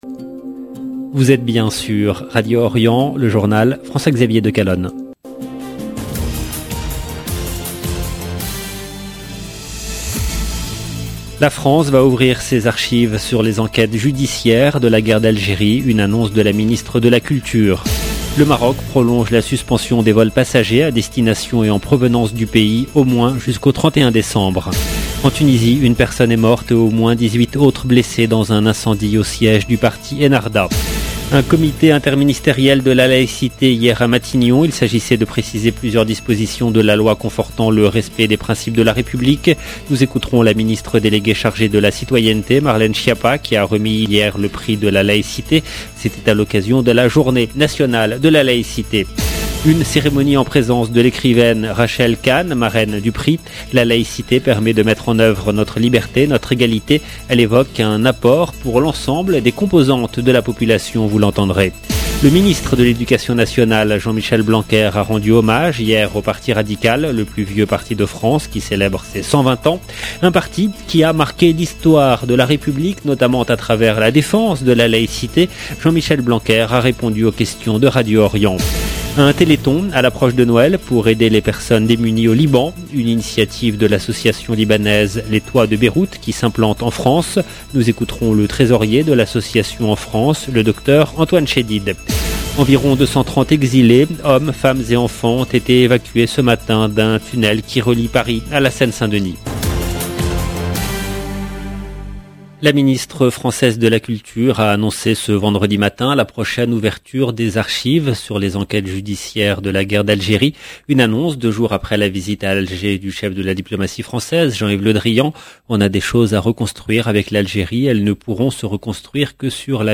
LB JOURNAL EN LANGUE FRANÇAISE
Jean-Michel Blanquer a répondu aux questions de Radio Orient.